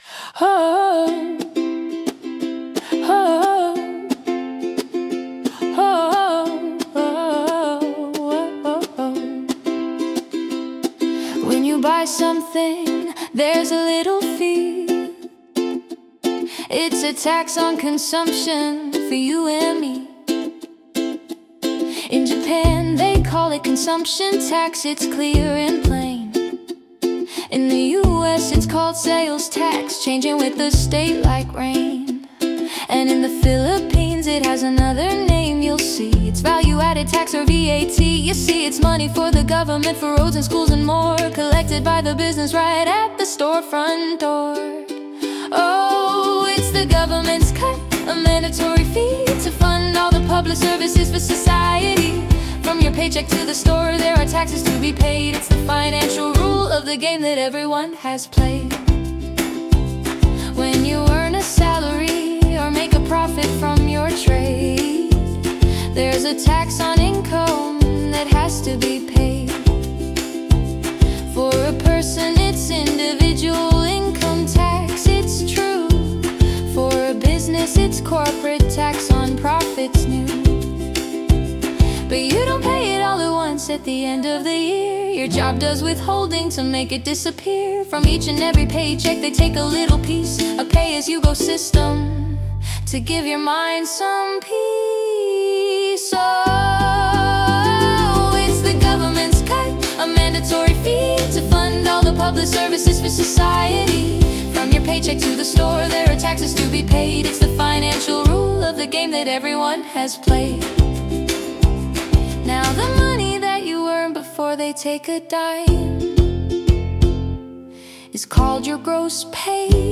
The Sing Along Experience